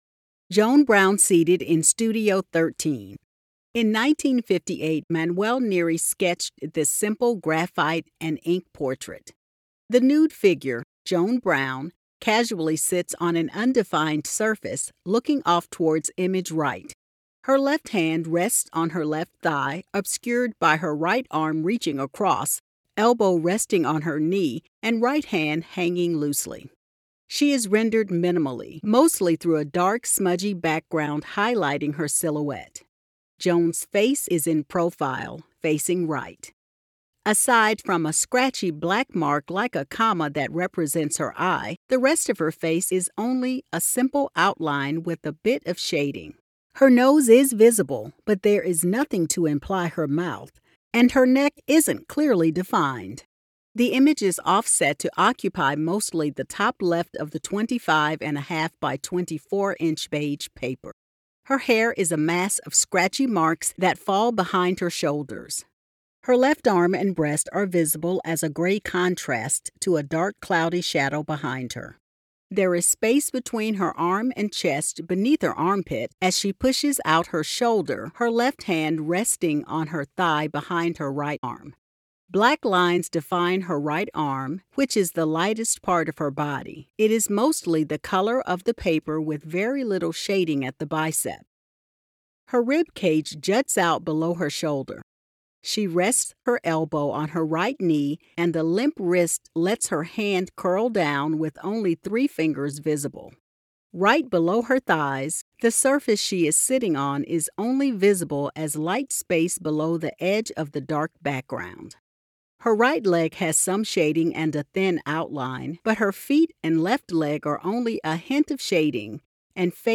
Audio Description (02:42)